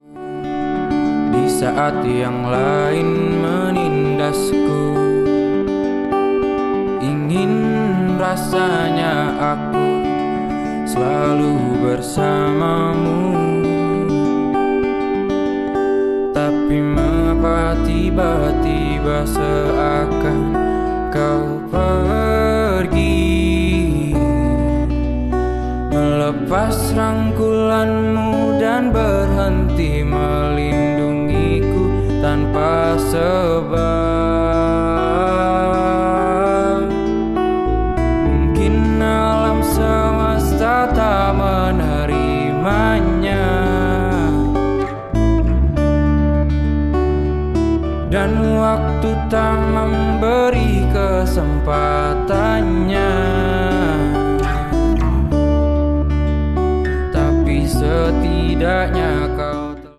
#8dmusic #8daudio